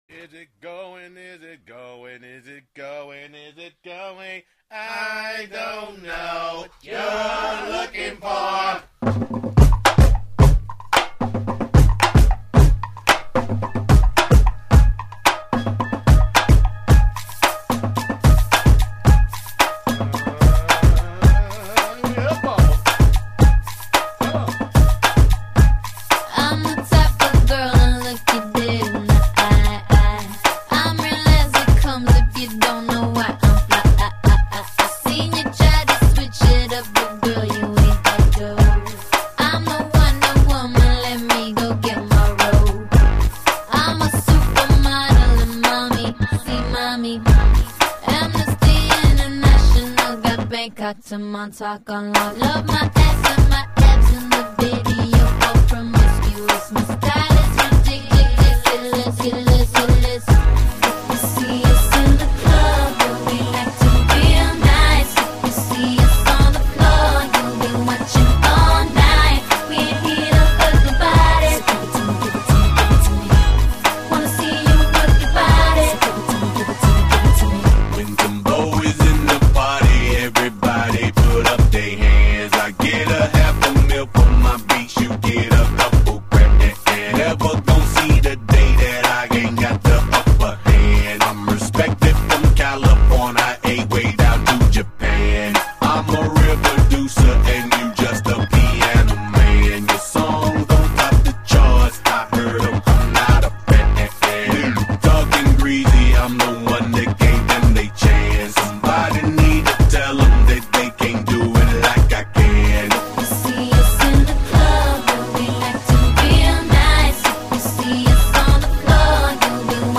Таги: RnB, С…РёС‚